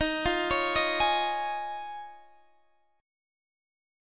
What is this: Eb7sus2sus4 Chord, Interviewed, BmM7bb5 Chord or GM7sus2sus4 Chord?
Eb7sus2sus4 Chord